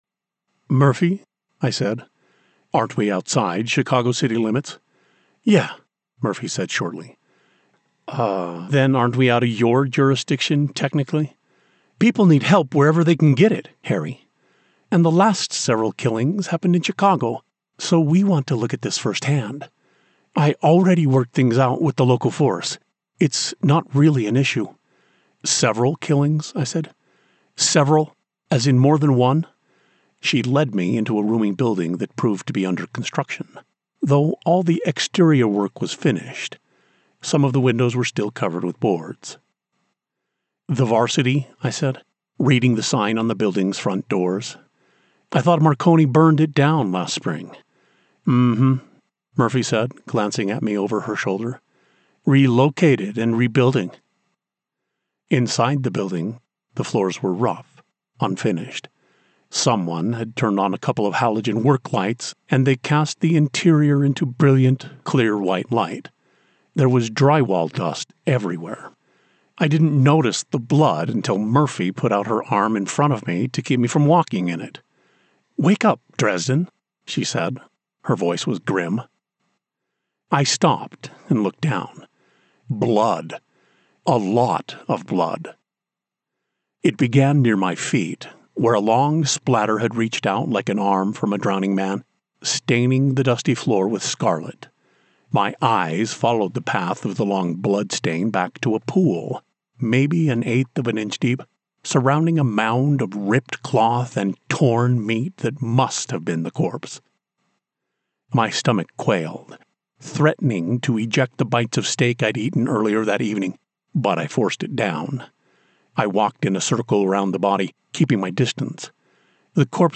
Rich • Robust • Trustworthy
A warm embrace of fatherly wisdom.
Mystery/Thriller • MF Dialogue
Mid-West American